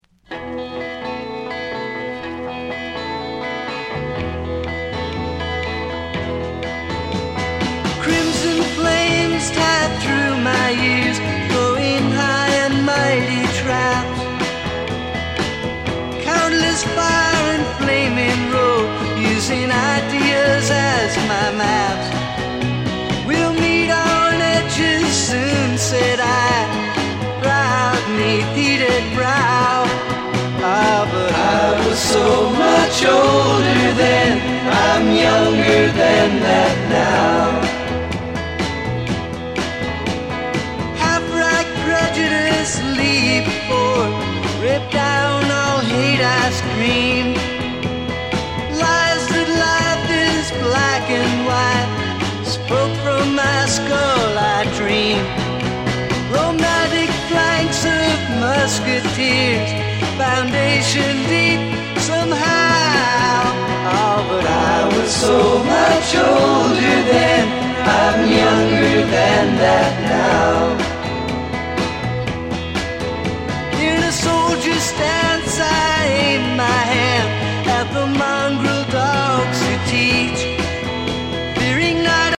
西海岸フォークロックバンド